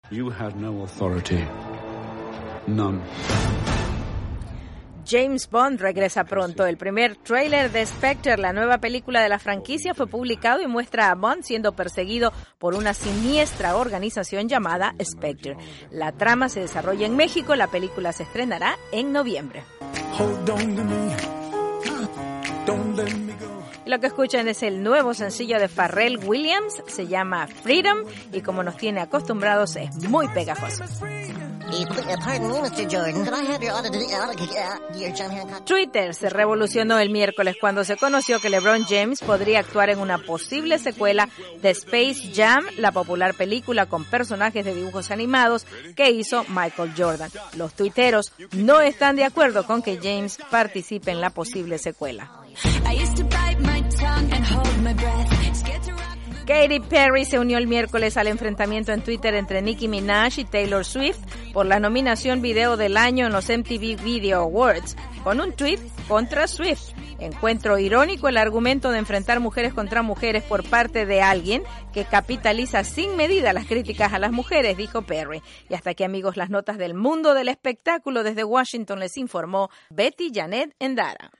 VOA: Noticias del Entretenimiento